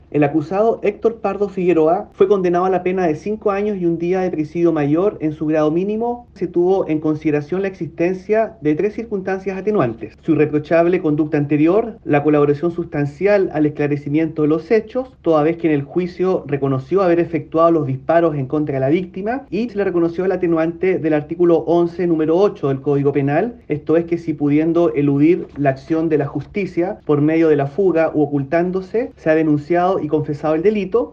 Según dijo el juez Gino Viale, se dieron 5 años de condena por lo ocurrido. Además, explicó las circunstancias atenuantes que hicieron llegar a la decisión del periodo de condena.